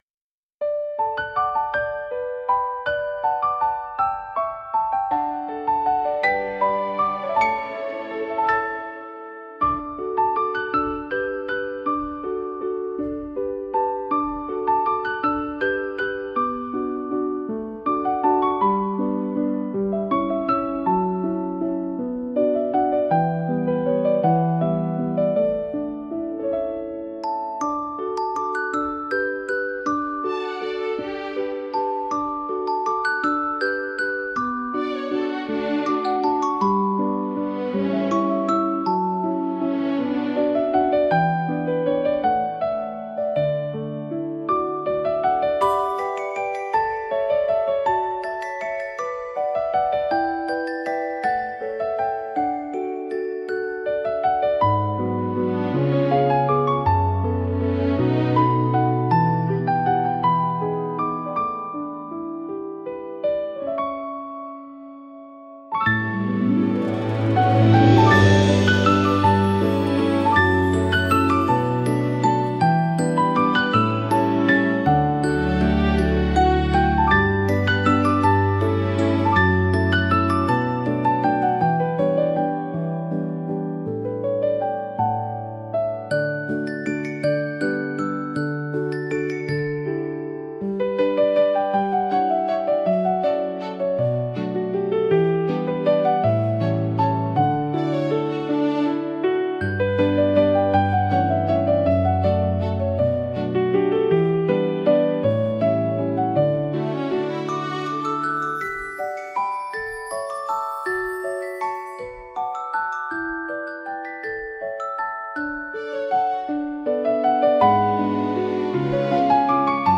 • Жанр: Детские песни
Легкая, запоминающаяся мелодия.